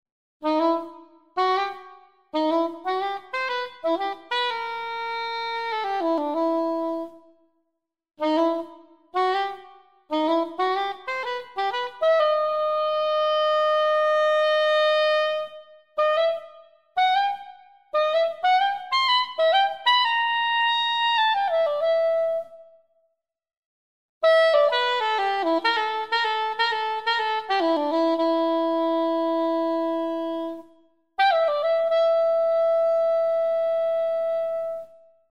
Beautiful sound and great intonation, thanks !